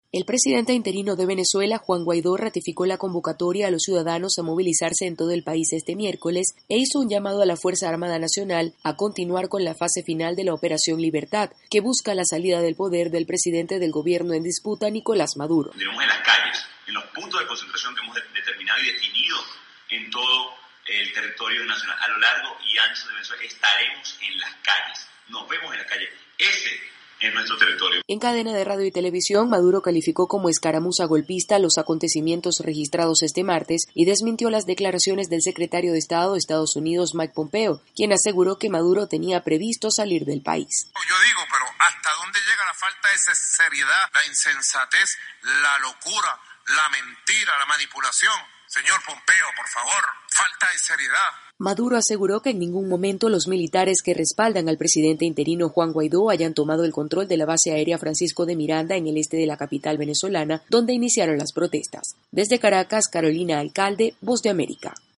VOA: Informe desde Venezuela
informa desde Caracas